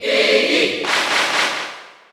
Category: Bowser Jr. (SSBU) Category: Crowd cheers (SSBU) You cannot overwrite this file.
Iggy_Cheer_Dutch_SSBU.ogg